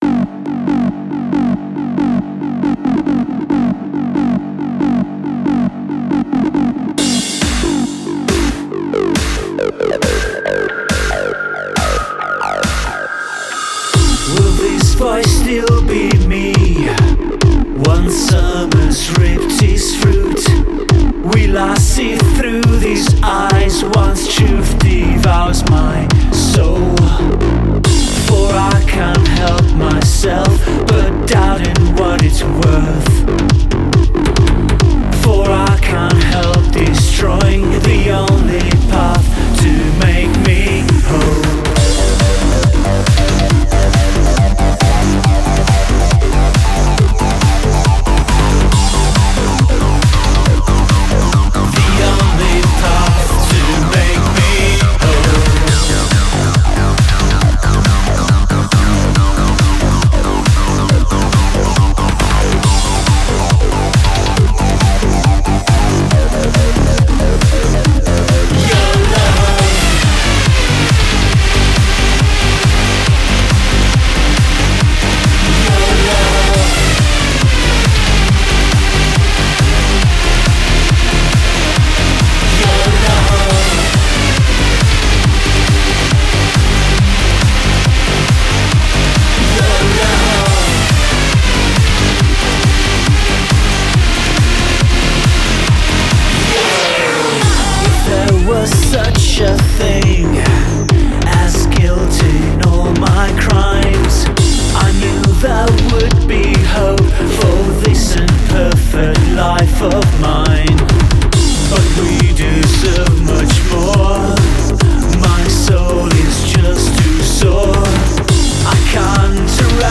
The newest in industrial, gothic, synthpop, post-punk, and shoegaze music, requests, the silly question, and more.